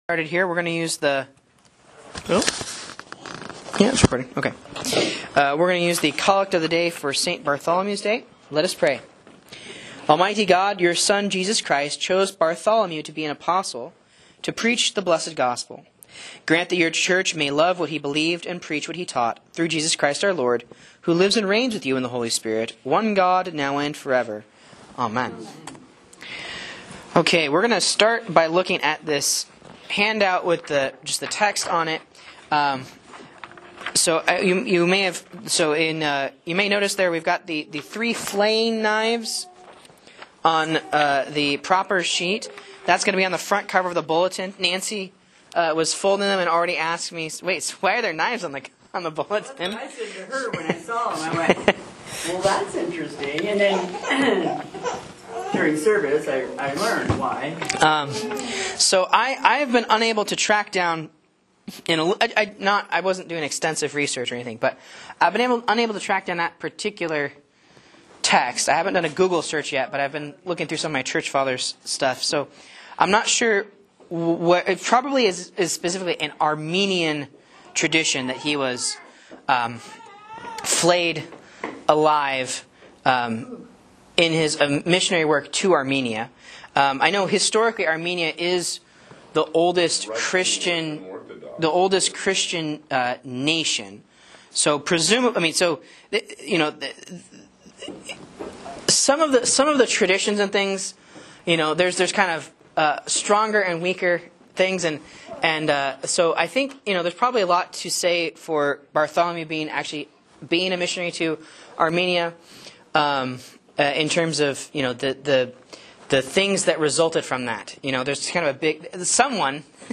Sermons and Lessons from Faith Lutheran Church, Rogue River, OR
A Bible Class on the Readings for St. Bartholomew's Day